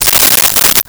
Coins In Hand 02
Coins in Hand 02.wav